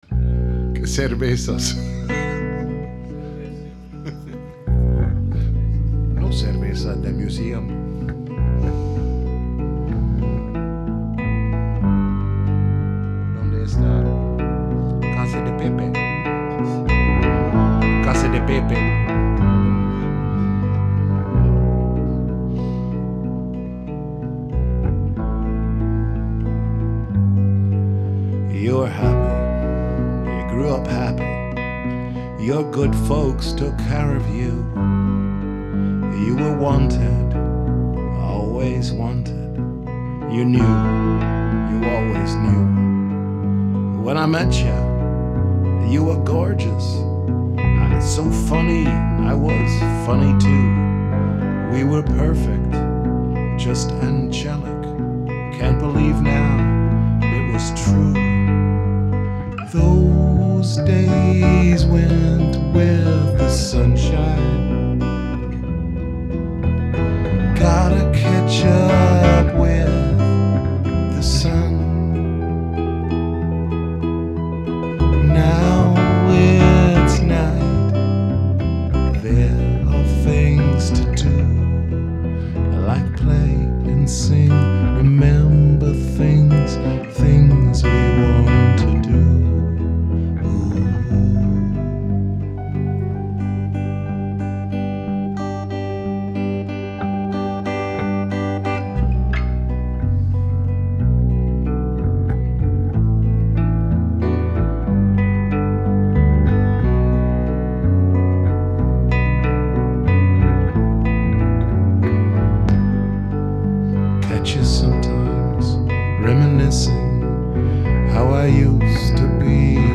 Rehearsals 25.2.2012